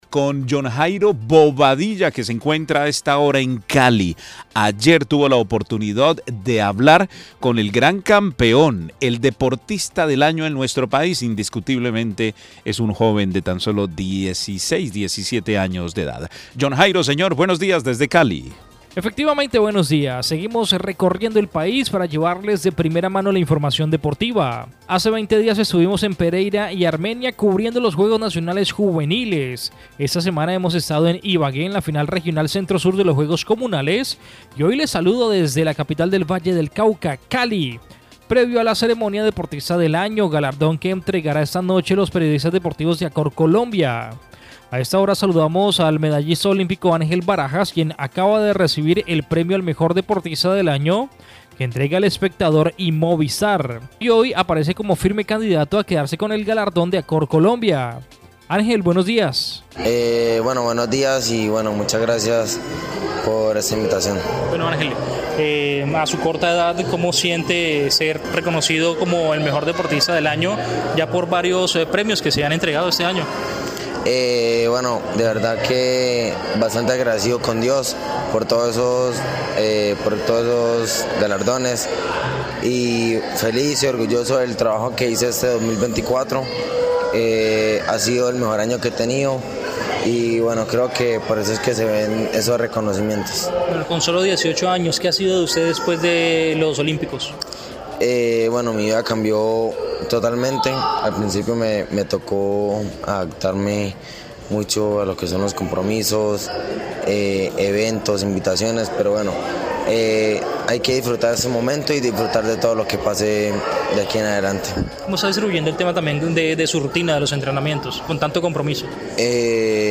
Hace 20 días estuvimos en Pereira y Armenia cubriendo los Juegos Nacionales Juveniles, esta semana hemos estado en Ibagué en la final regional Centro Sur de los Juegos Comunales y hoy les saludo desde la capital del Valle de Cauca, Cali, previo a la ceremonia deportista del año, galardón que entregará esta noche los periodistas deportivos de Acord Colombia, a esta hora saludamos al medallista Olímpico Ángel Barajas quién acaba de recibir el premio al mejor deportista del año que entrega el Espectador y Movistar y hoy firme candidato a quedarse con el galardón de acord Colombia.